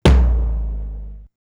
Index of dough-samples/ tidal-drum-machines/ machines/ LinnLM2/ linnlm2-lt/
LM-2_TOM_3_TL.wav